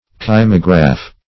Kymograph \Ky"mo*graph\, n. [Gr.